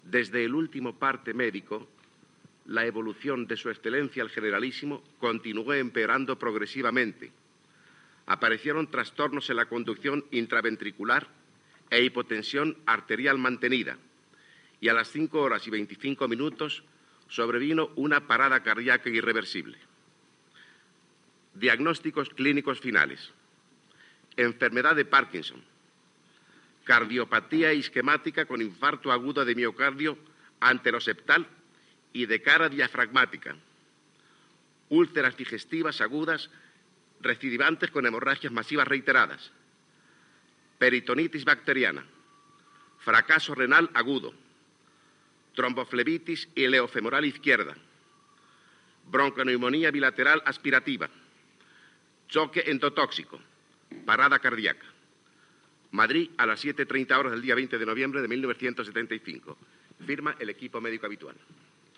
Últim comunicat mèdic de l'estat de salut del "Generalísiom" Francisco Franco, ingressat a la ciutat sanitària La Paz de Madrid, poques hores abans que s'anunciés la seva mort. El llegeix el ministre d'informació i turisme León Herrera.
Informatiu